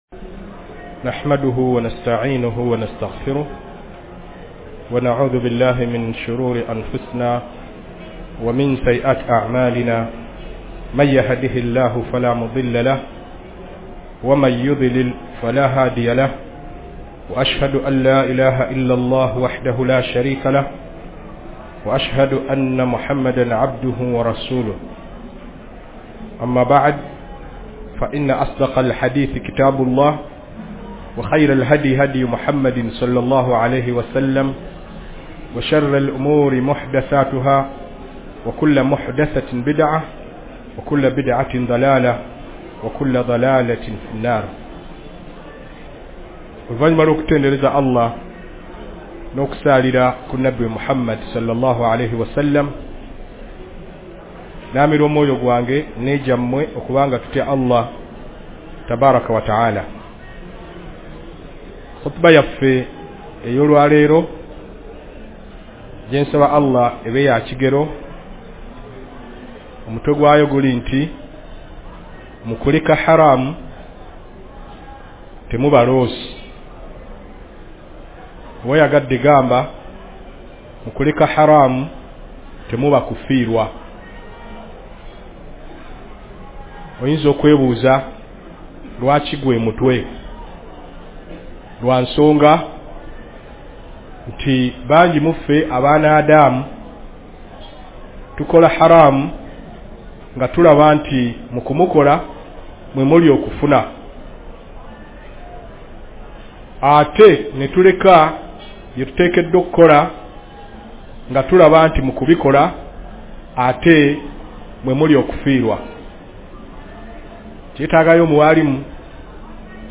JUMMA KHUTUB